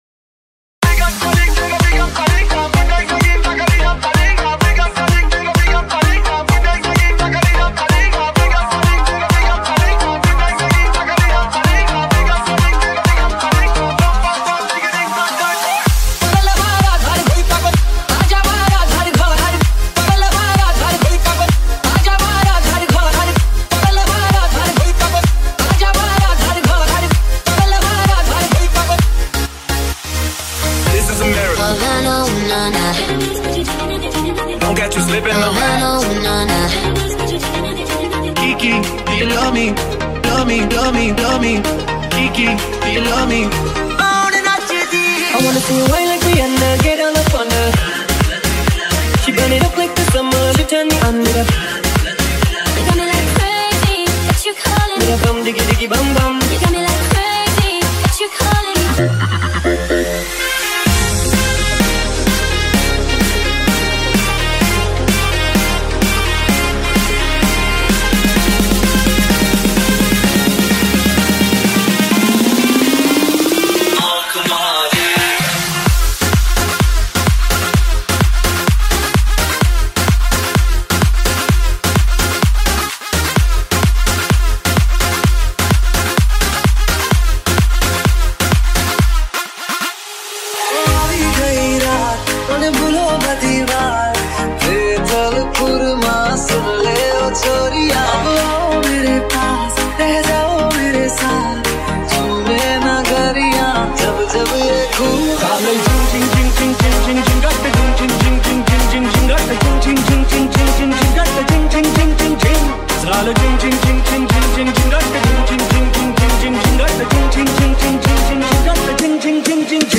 End Year Mashup 2022 Bollywood Party Mashup